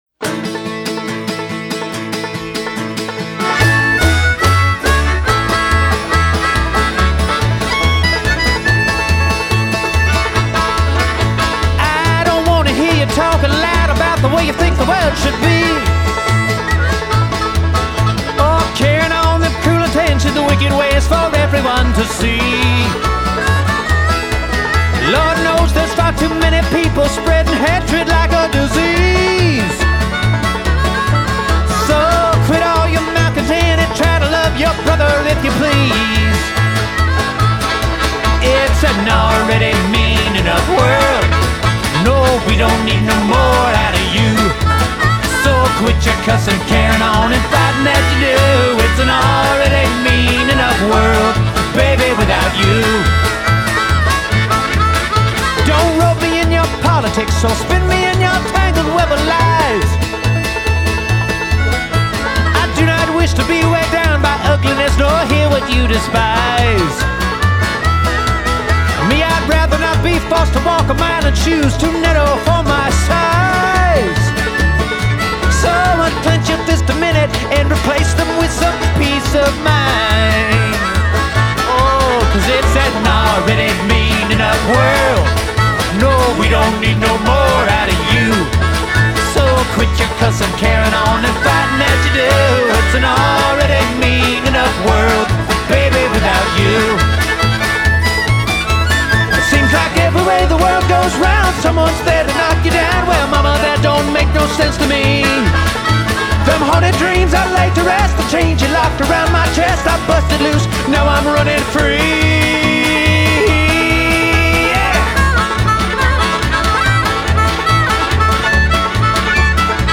This is a banjo/fiddle/upright bass stomping good time .
melodic boogie